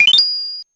Sound effect for when the player confirms a selection in menus.